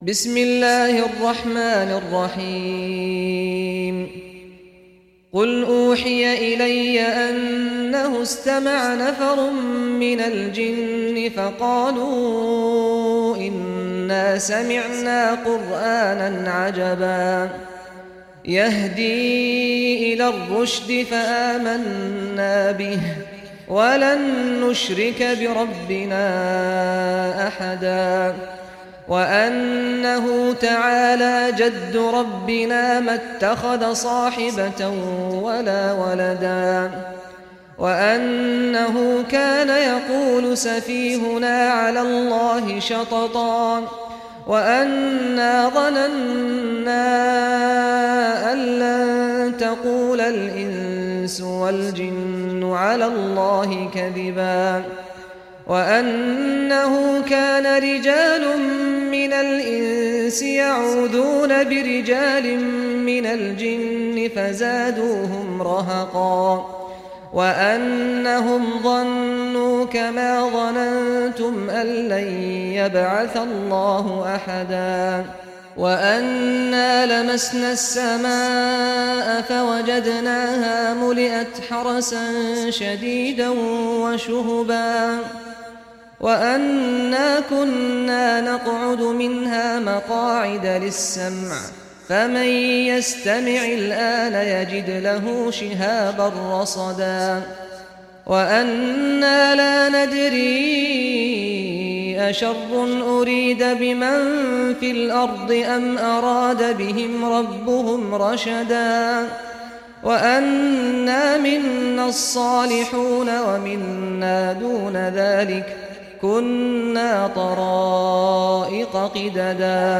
Surah al-Jinn, listen or play online mp3 tilawat / recitation in Arabic in the beautiful voice of Sheikh Saad al Ghamdi.